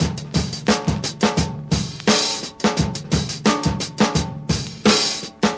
• 86 Bpm Breakbeat E Key.wav
Free drum loop sample - kick tuned to the E note. Loudest frequency: 2503Hz
86-bpm-breakbeat-e-key-nZL.wav